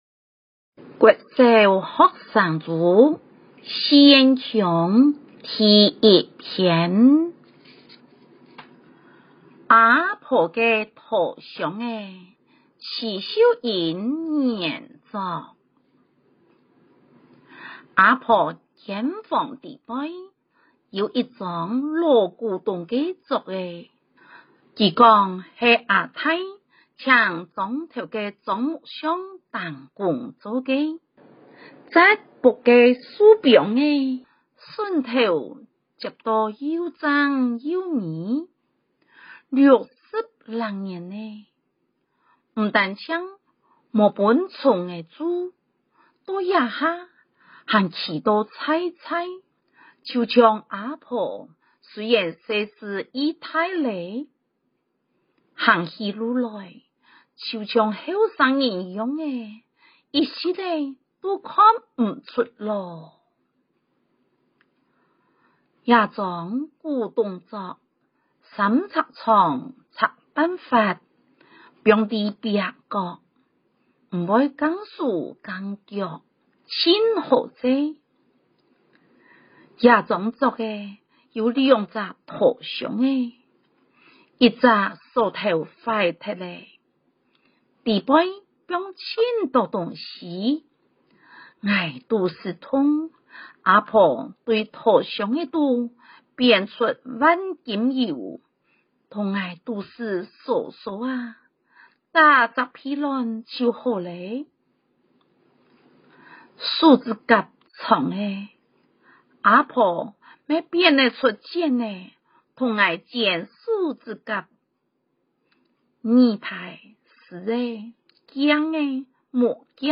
基隆市立信義國民小學 - 113學年校內客語朗讀稿四縣腔音檔
客語朗讀四縣腔-第一篇阿婆个拖箱仔mp3.mp3